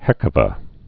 (hĕkə-və) Slang